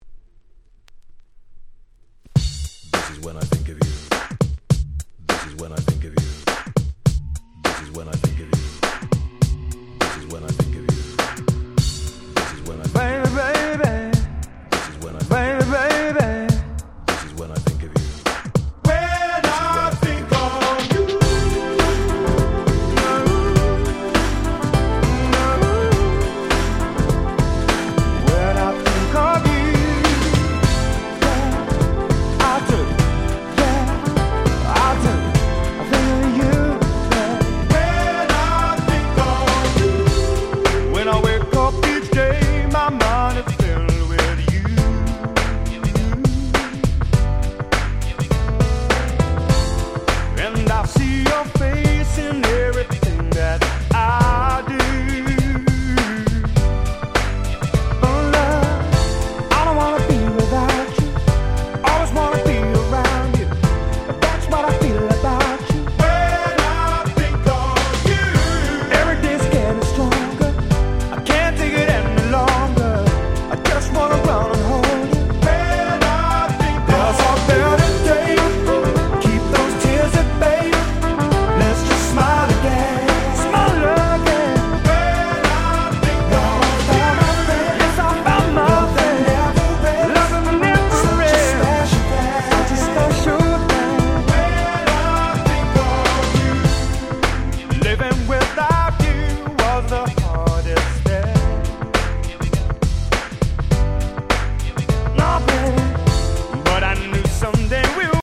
95' Very Nice UK R&B !!
爽快な踊れる男性Vocal R&B !!
アシッドジャズ R&B 90's